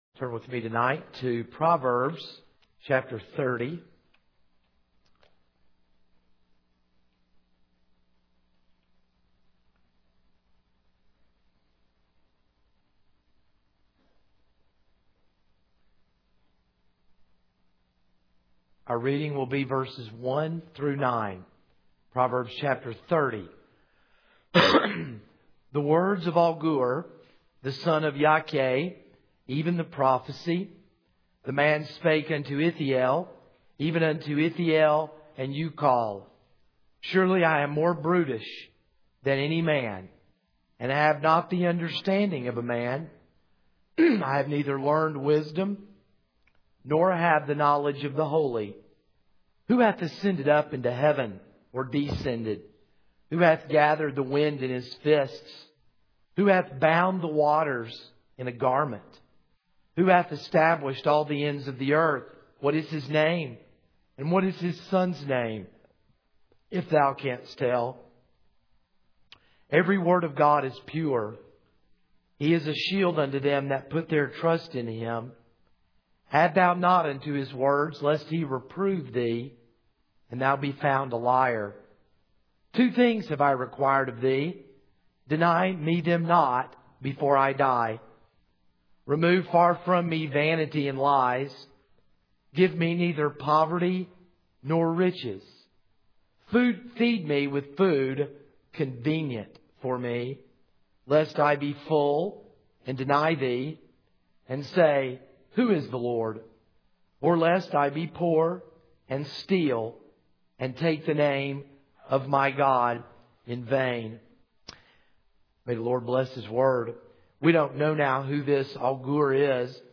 This is a sermon on Proverbs 30:1-9.